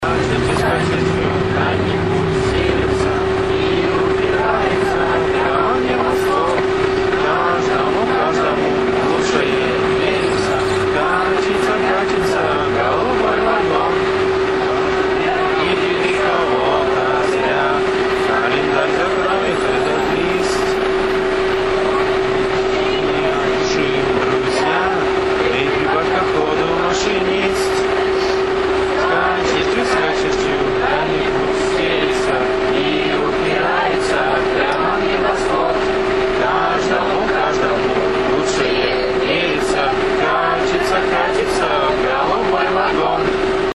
Итак, первый хоромоб имел место быть ровно в прошедшее воскресенье.
Как и положено, петь моберы начинали постепенно. Пели все отменно и ошибок почти не наблюдалось.
Диктофон всё исправно и незаметно записывал.
Звуковой файл, свидетельствующий нарушение общественного спокойствия в автобусе номер "66" на отрезке Голубой Огонек – Академия транспорта.